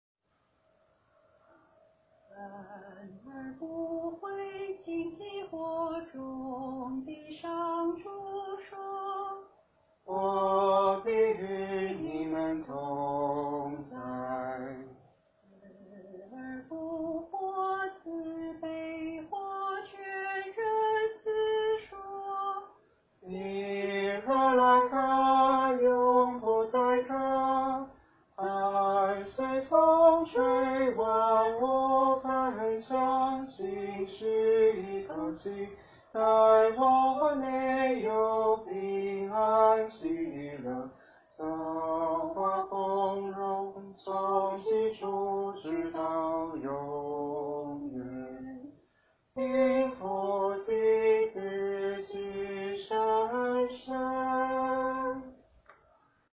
整首乐曲安稳沉静，三位一体娓娓道来，有如天使在传报颂歌。
最后有如号角声般 唤出圣三的名号。
清唱.mp3